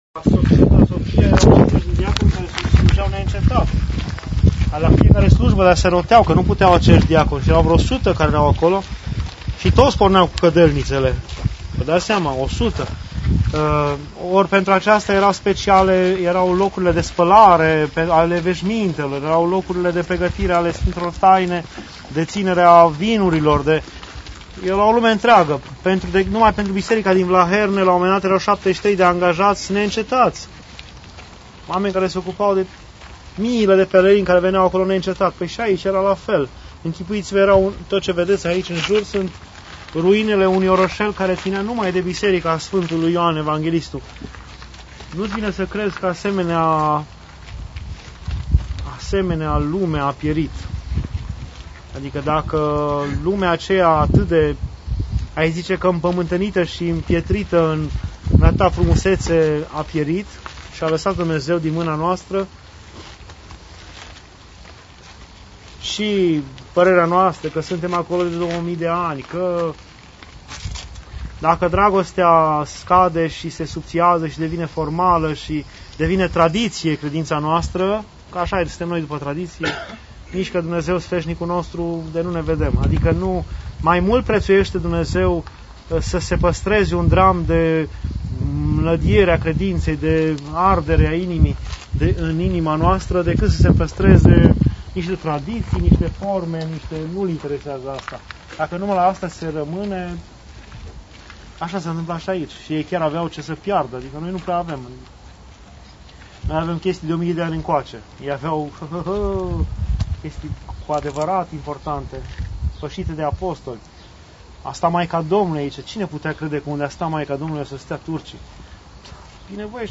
Pelerinaj 2 Turcia, Grecia